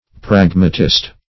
Pragmatist \Prag"ma*tist\, n.